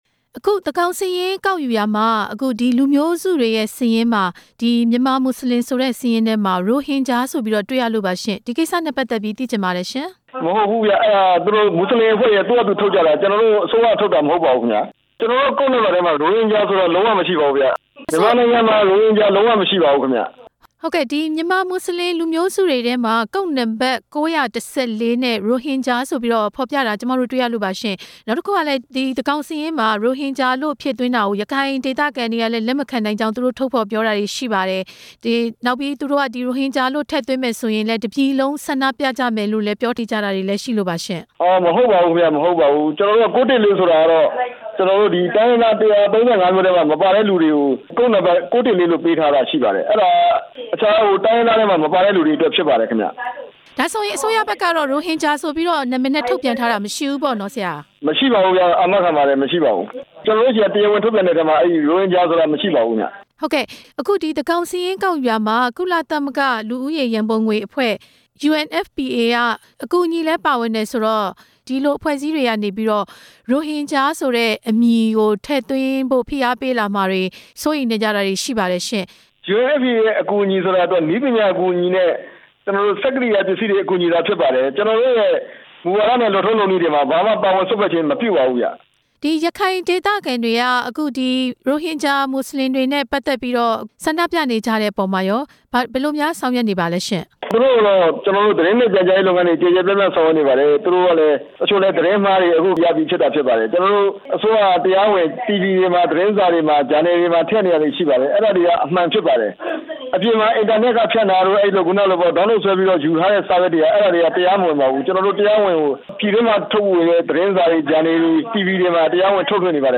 ညွှန်ကြားရေးမှူးချူပ် ဦးမြင့်ကြိုင်နဲ့ မေးမြန်းချက်